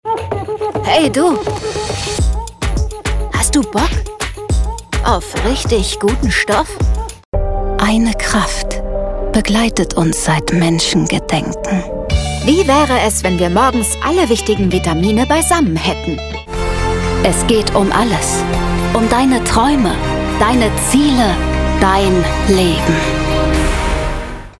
Sprecherin für Werbung, Imagefilme, Hörbücher, Dokumentationen und Co.
Sprechprobe: Sonstiges (Muttersprache):